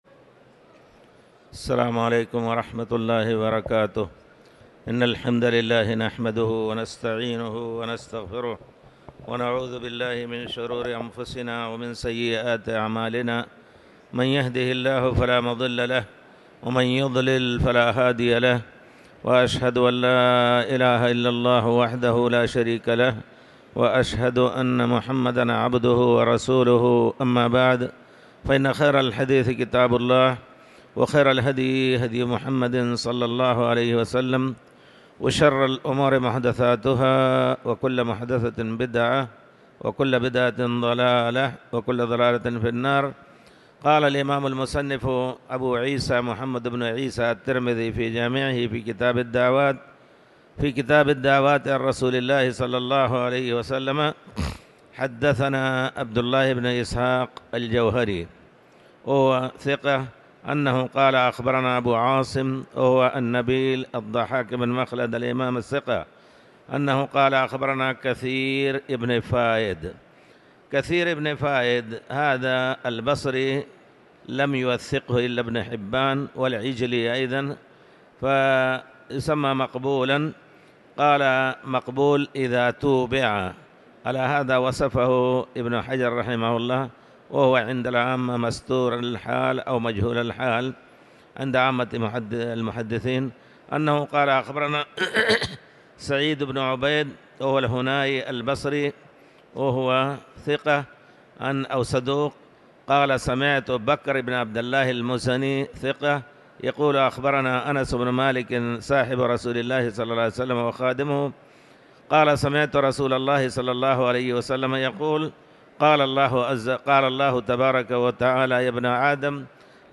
تاريخ النشر ١٦ جمادى الآخرة ١٤٤٠ هـ المكان: المسجد الحرام الشيخ